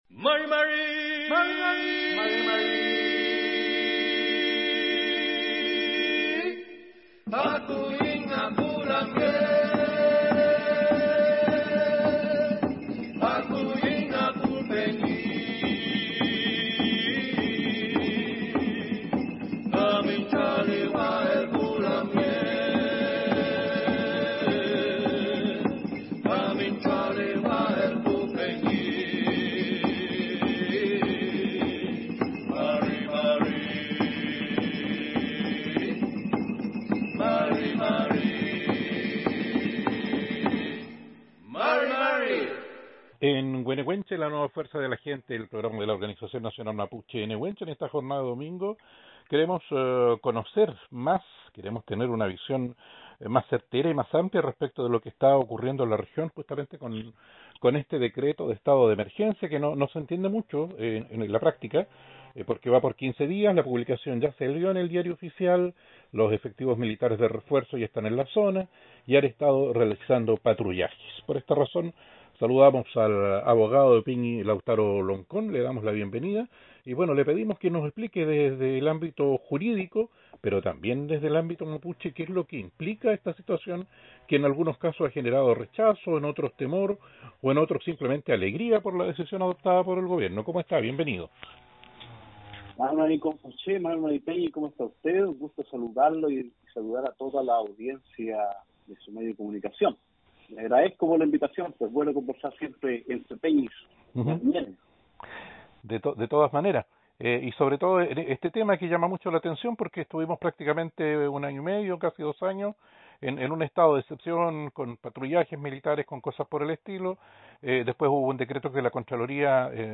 Tuvimos una interesante conversación de el estado de excepción vigente en el Wallmapu.